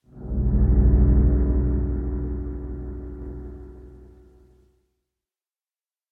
ambient / cave
should be correct audio levels.
cave7.ogg